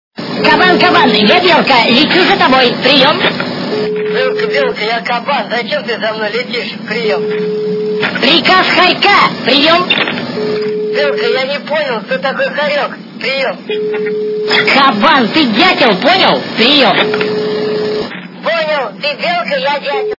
» Звуки » Люди фразы » Голос - Кабан, кабан я белка лечу за тобой, прием
При прослушивании Голос - Кабан, кабан я белка лечу за тобой, прием качество понижено и присутствуют гудки.